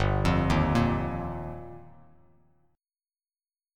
Gm7#5 Chord